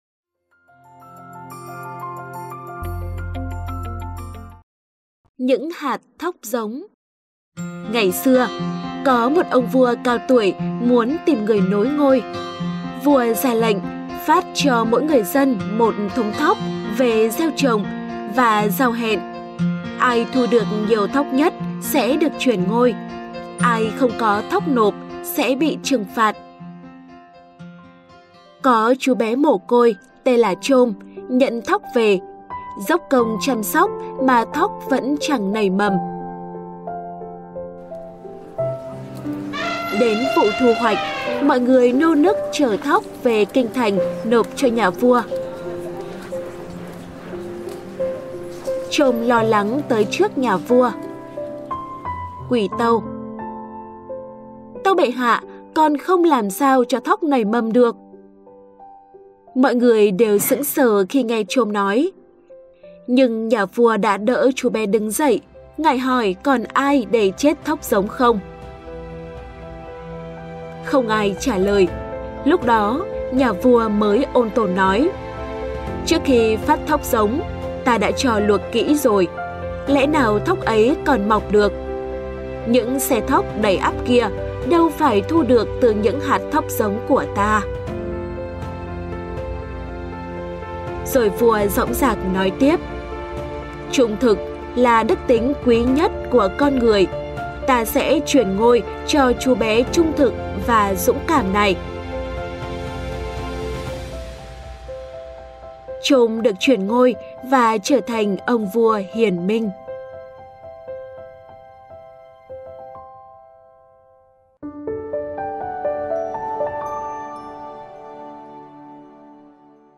Sách nói | Những hạt thóc giống